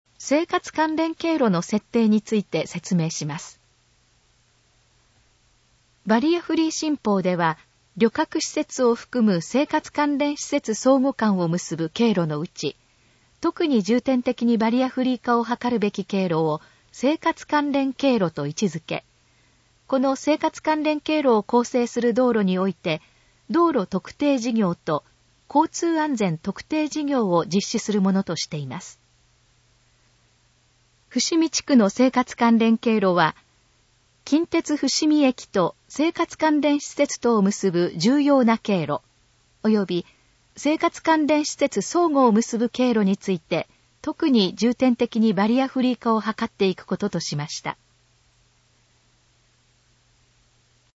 以下の項目の要約を音声で読み上げます。
ナレーション再生 約200KB